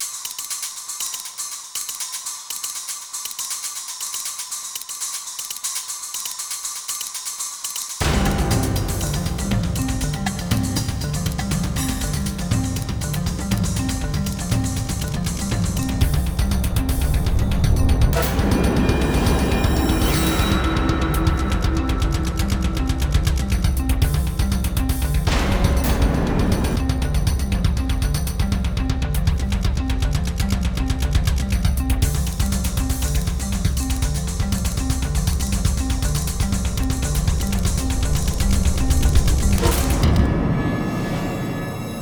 Sintonía de documental 1 (bucle)
melodía
sintonía
Sonidos: Música